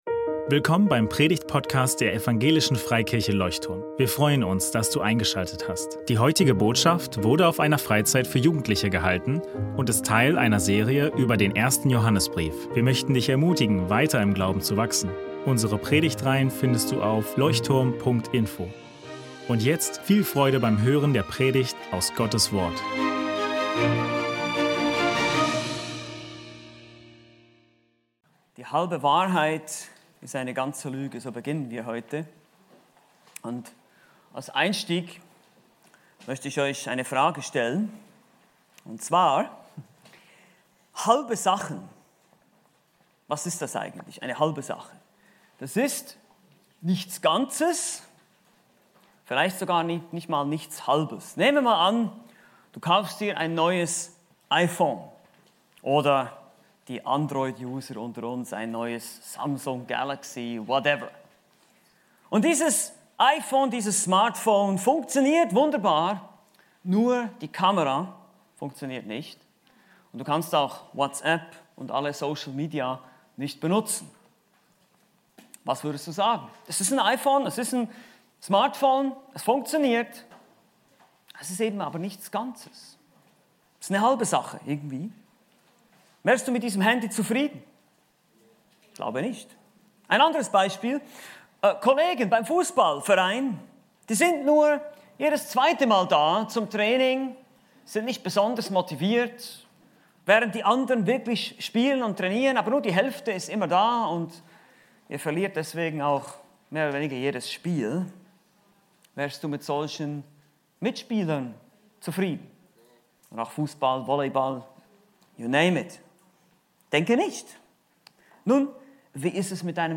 Die halbe Wahrheit ist eine ganze Lüge ~ Leuchtturm Predigtpodcast Podcast